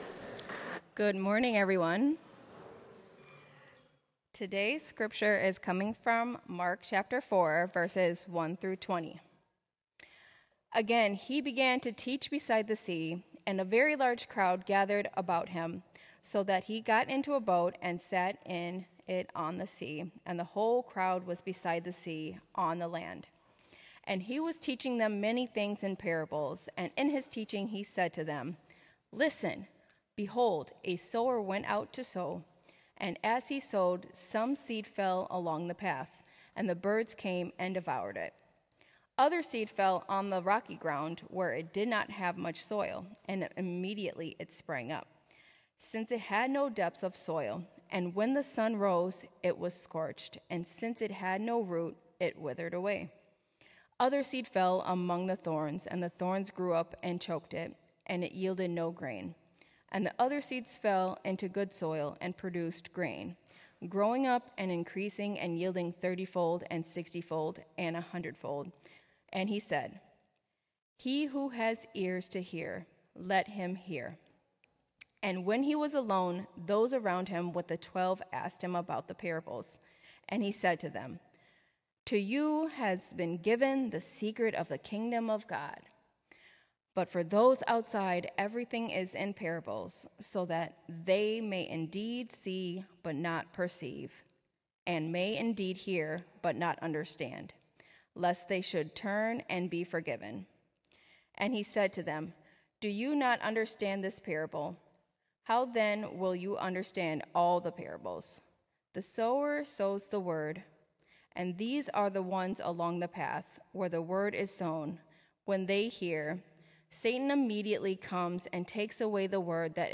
Sermon: Mark: Embracing the Gospel
sermon-mark-embracing-the-gospel.m4a